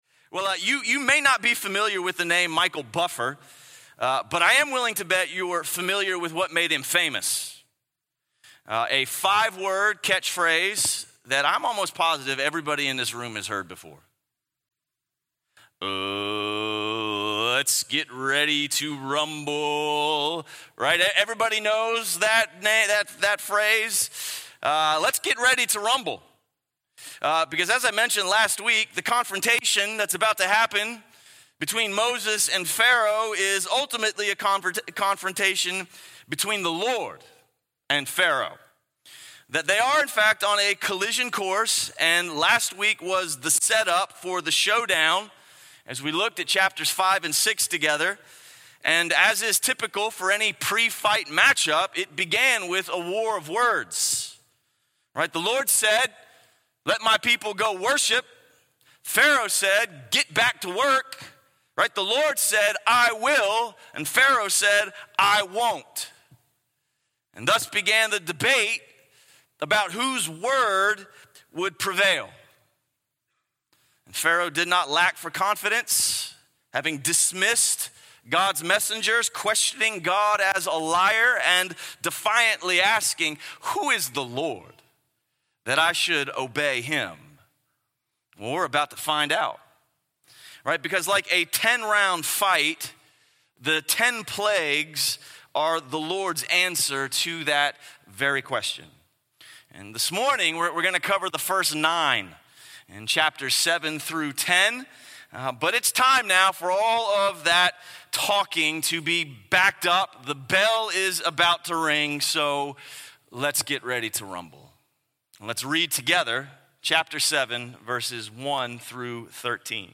A message from the series "Ekklēsía."